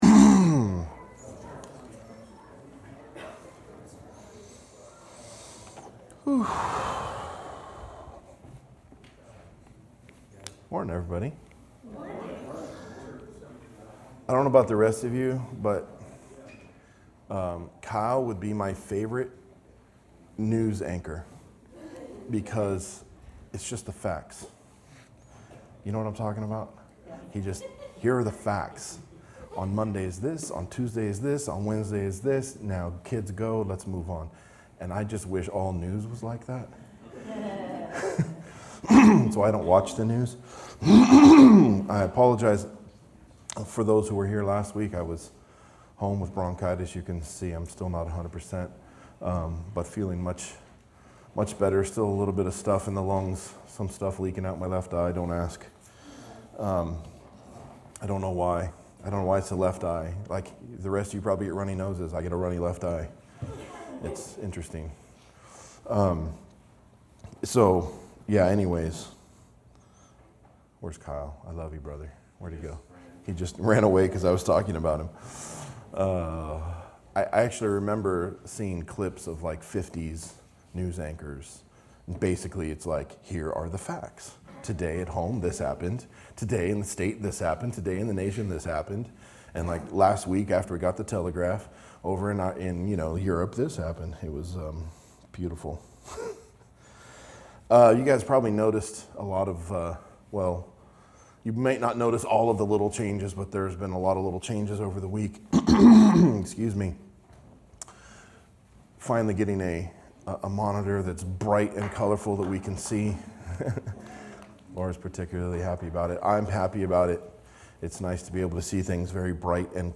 Verse by verse exposition of Matthew's Gospel